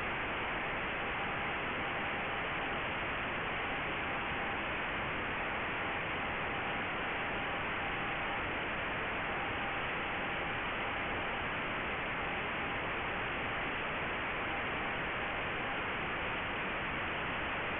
He made already a program in Python that does create a WAV file of a Morsecode signal and adds noise.
Morsecode test signals!
The speed is 6 words per minute (dot time 0.2 sec.).
Audio file -12 dB SNR, 6 words per minute